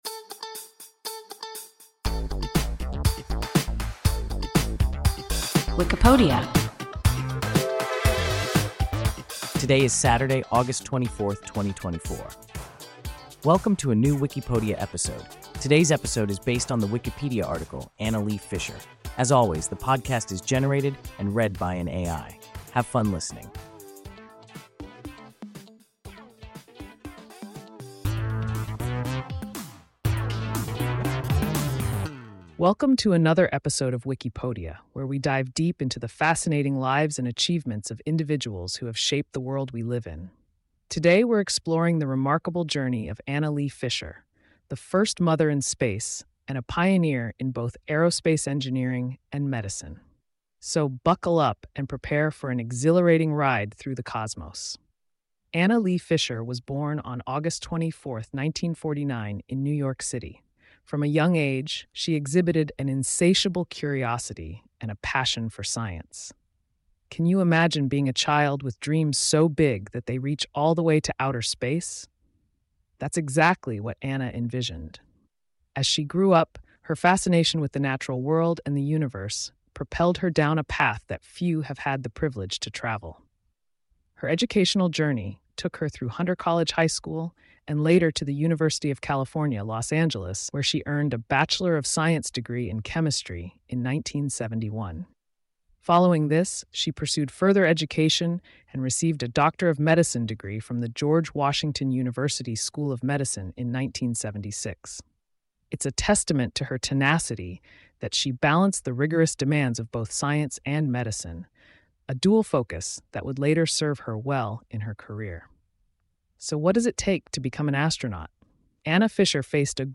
Anna Lee Fisher – WIKIPODIA – ein KI Podcast